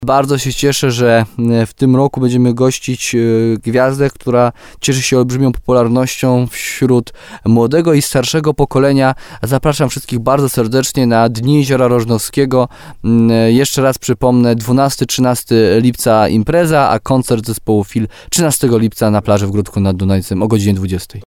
Jeszcze raz przypomnę 12-13 lipca impreza, a koncert zespołu Feel 13 lipca na plaży w Gródku nad Dunajcem o godz. 20.00 – mówi Jarosław Baziak, wójt gminy Gródek nad Dunajcem.